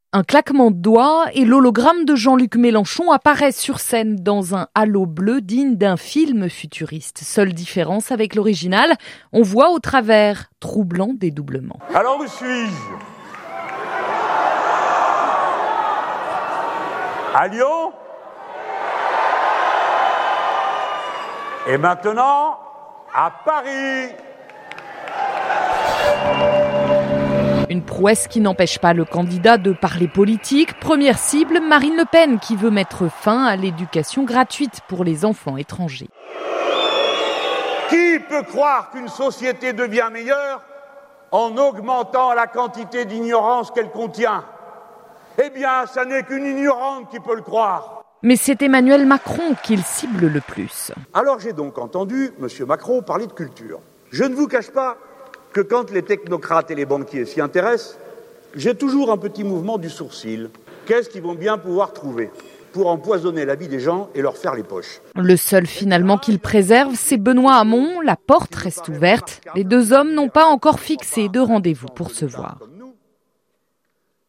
C’est son hologramme qui a parlé à la foule rassemblée aux Docks de Paris à Aubervilliers.